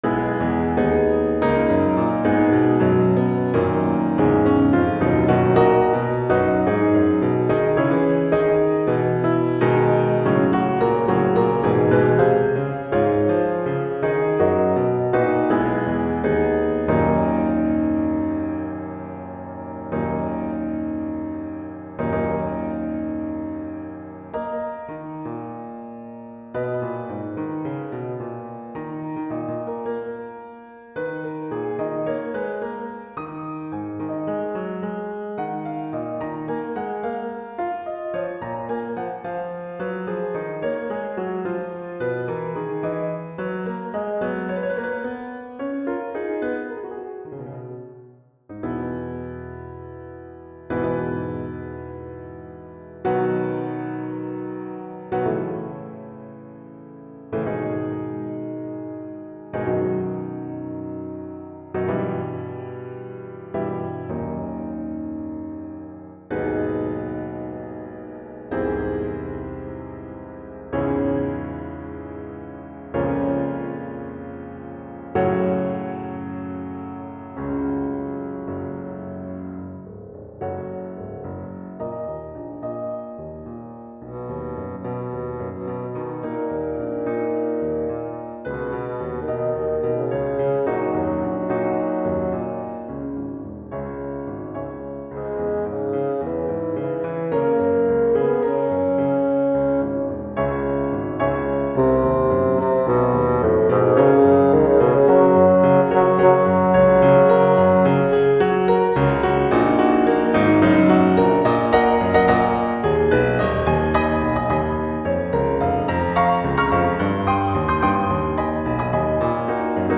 Demoaufnahme 11/27
Tonart: Ces-Dur / Tempo: Moderato
(In der Demoaufnahme wurde die Gesangstimme durch ein Fagott ersetzt.)